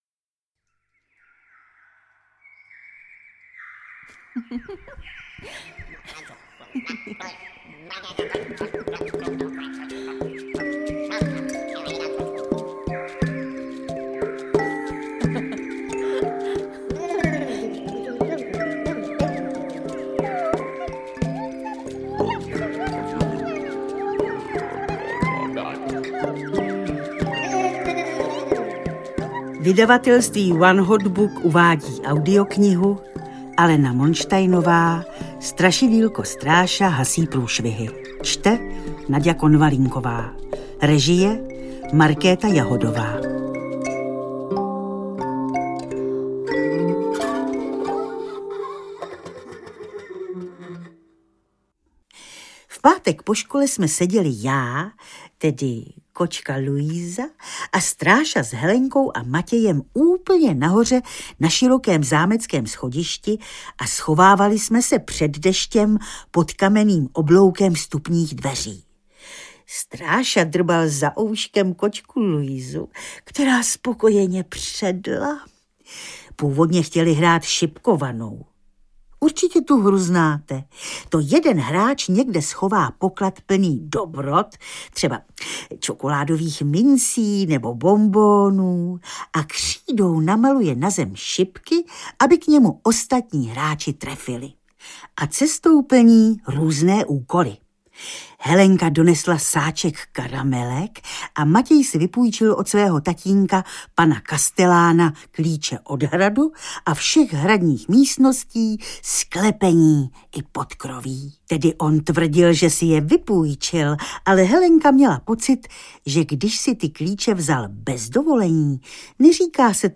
Strašidýlko Stráša hasí průšvihy audiokniha
Ukázka z knihy
• InterpretNaďa Konvalinková